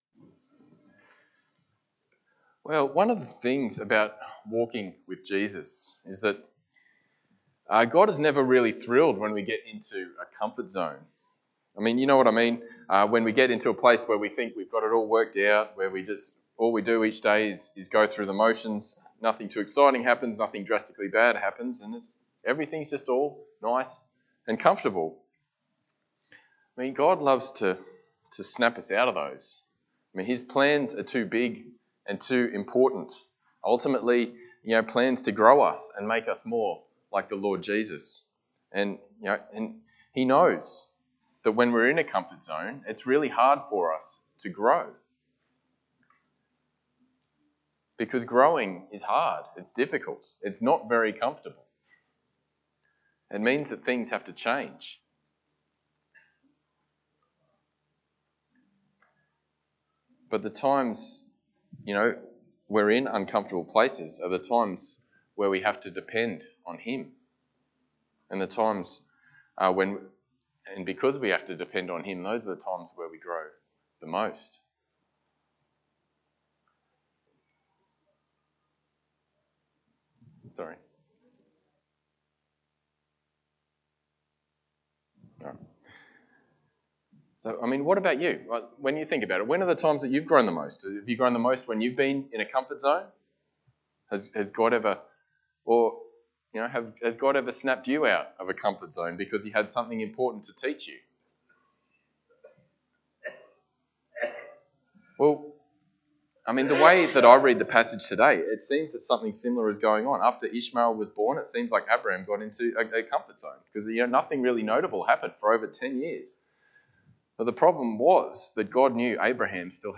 Passage: Genesis 17 Service Type: Sunday Morning